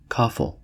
Ääntäminen
US : IPA : /ˈkɔfl̩/